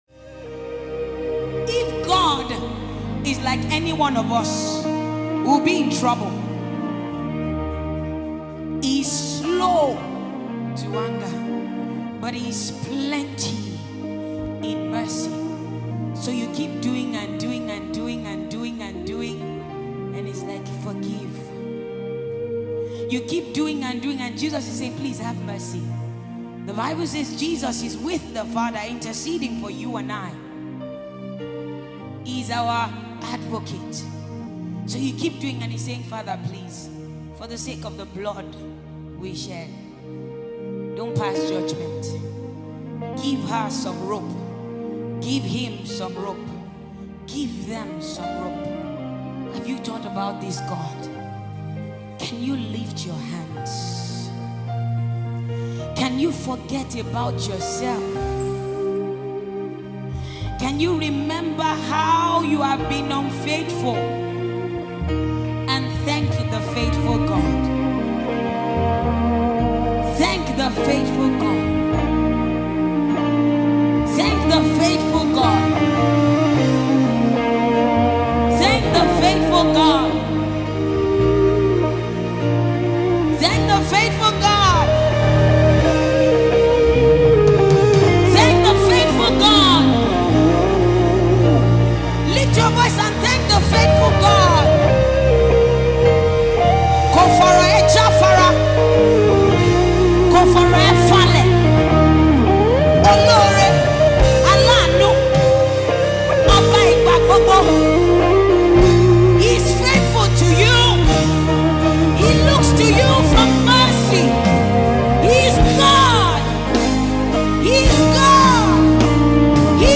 Famous Nigerian gospel singer